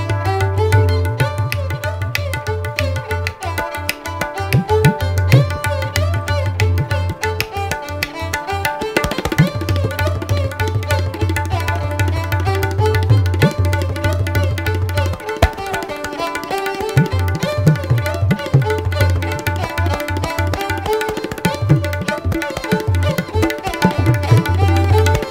Category: Tabla Ringtones